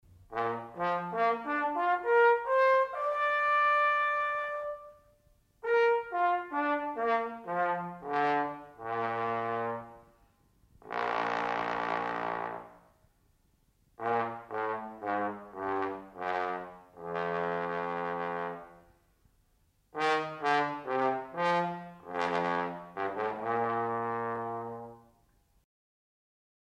Il trombone produce un suono profondo e grave, ma nello stesso tempo sa creare anche sonorità dolci e vellutate.
suono del trombone
- caratteristico glissando del trombone
- trombone con sordina
trombone_suono.mp3